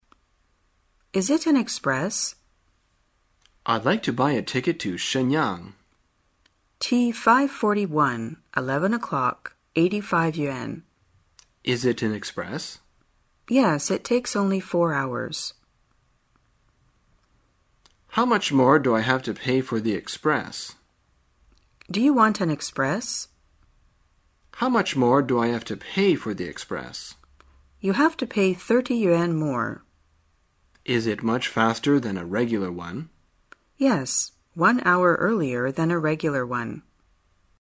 在线英语听力室生活口语天天说 第96期:怎样买快车票的听力文件下载,《生活口语天天说》栏目将日常生活中最常用到的口语句型进行收集和重点讲解。真人发音配字幕帮助英语爱好者们练习听力并进行口语跟读。